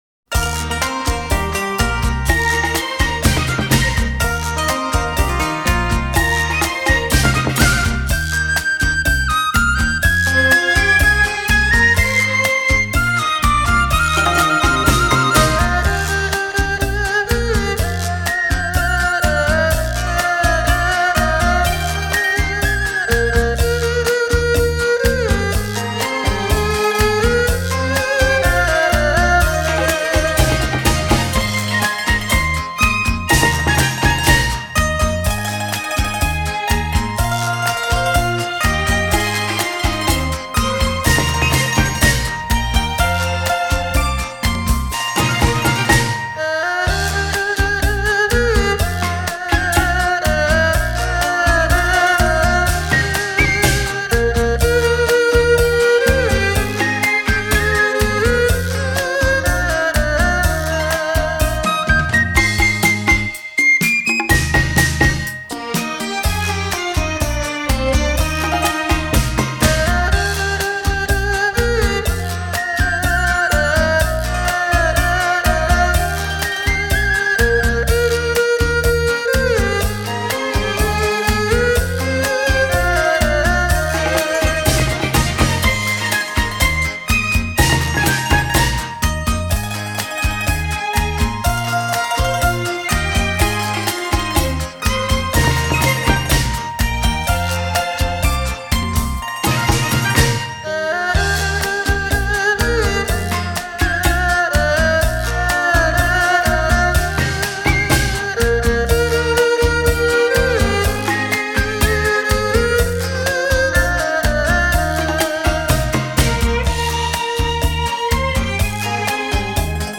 华乐精彩争鸣 欢乐洒遍人间
鼓声喧闹绕场欢庆 百乐争鸣吉祥如意